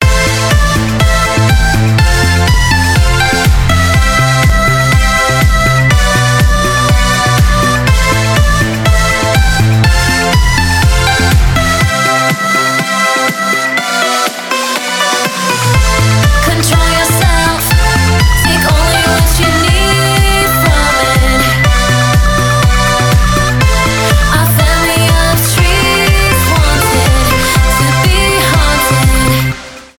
progressive house , танцевальные
громкие , edm